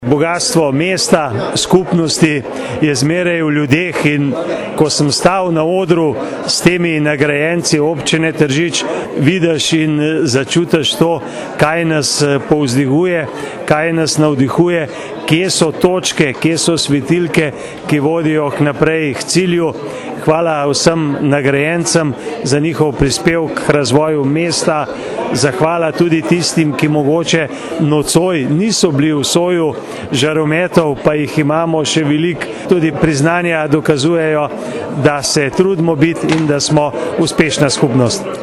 izjava_mag.borutsajoviczupanobcinetrzic_akademija.mp3 (927kB)